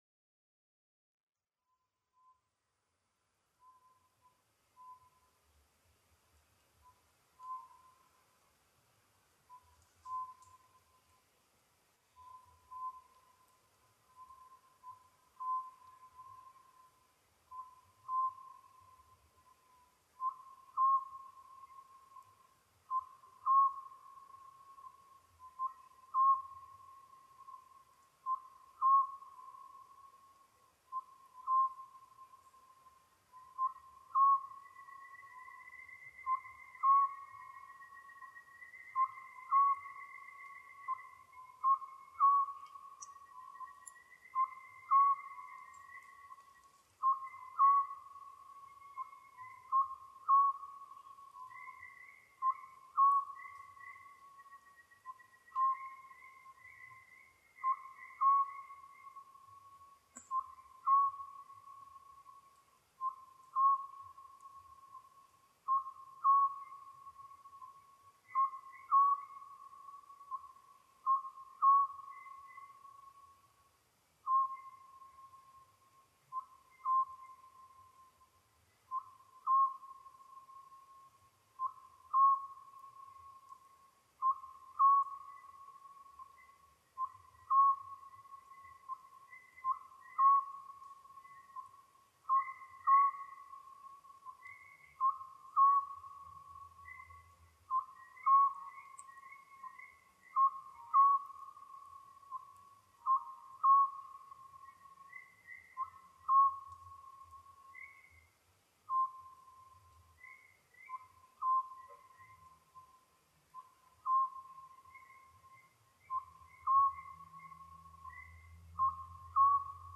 コノハズク　Otus scopsフクロウ科
日光市砥川上流　alt=550m
Mic: Panasonic WM-61A  Binaural Souce with Dummy Head
コッコーと繰り返し鳴き、遠くで別な個体が同じように鳴きます。 他の自然音：　カジカガエル